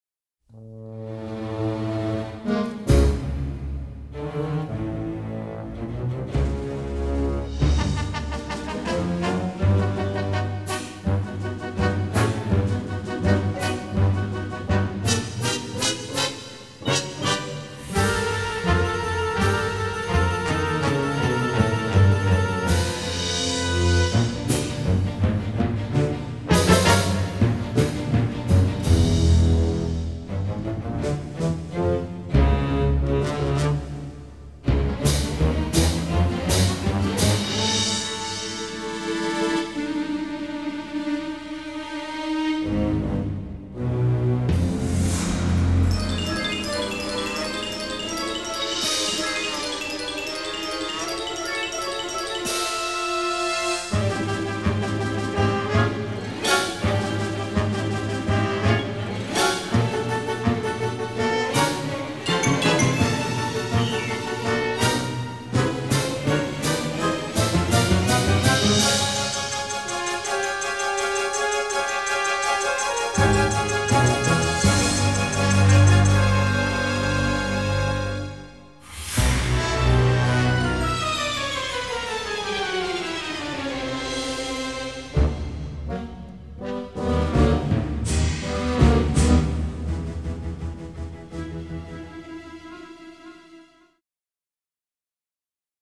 generous string section plus brass ensemble